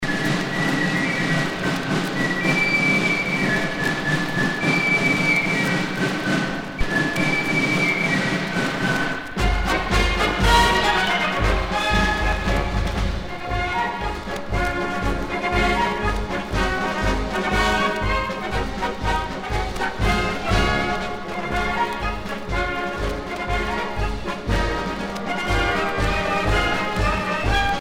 Fonction d'après l'analyste gestuel : à marcher
Pièce musicale éditée